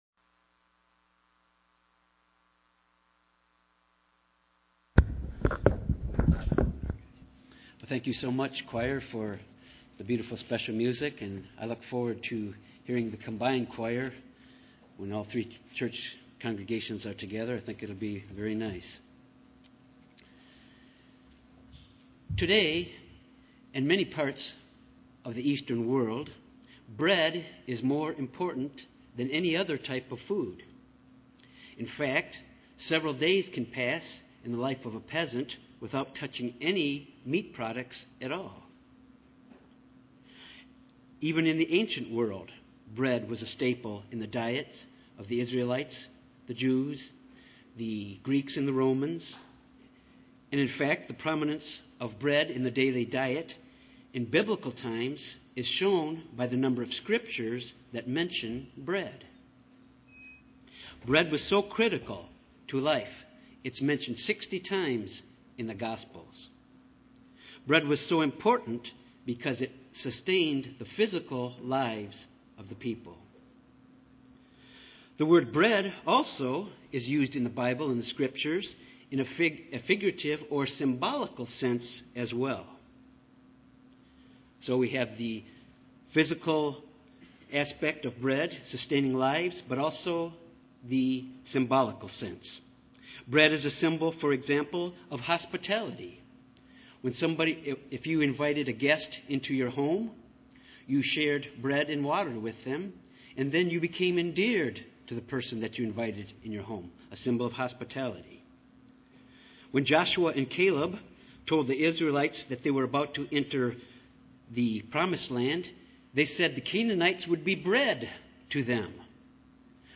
This sermon examines the importance of the Bread of Life for us as disciples of Jesus Christ. We need Jesus Christ, the Bread of Life, on a daily basis.